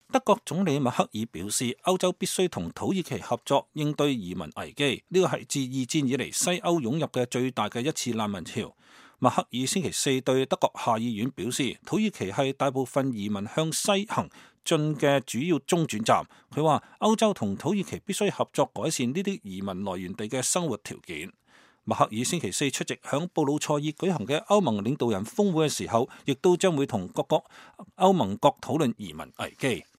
德國總理默克爾週四對德國下議院發表講話。